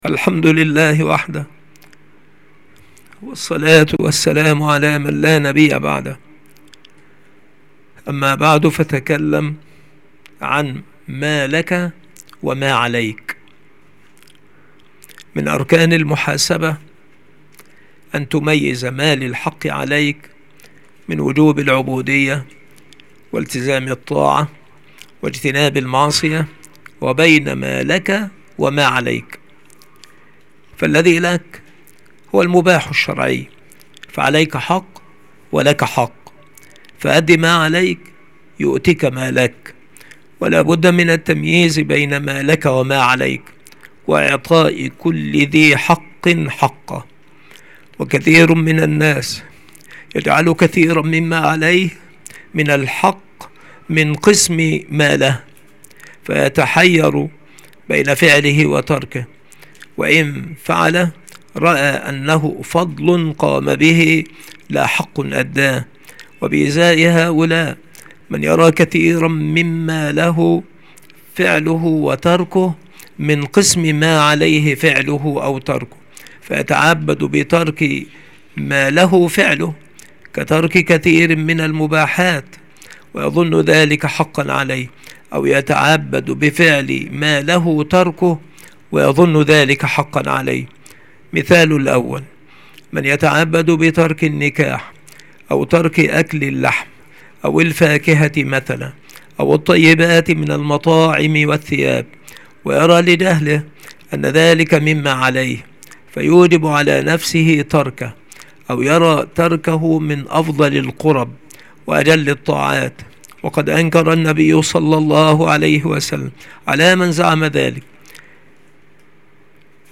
المحاضرة
المكتبة - سبك الأحد - أشمون - محافظة المنوفية - مصر